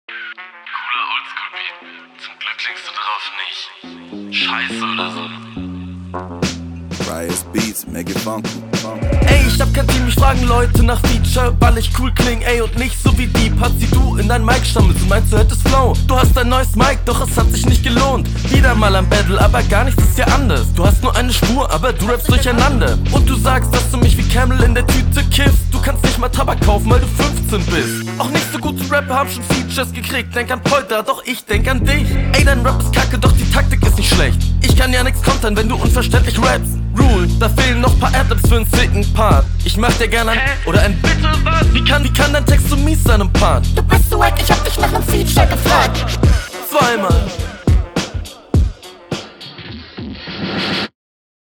Flow in Ordnung, Gegnerbezug war definitiv stark.
Flow ist echt Standard, Punches sind aber ganz gut.